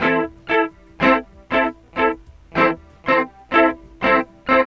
62 GUIT 1 -R.wav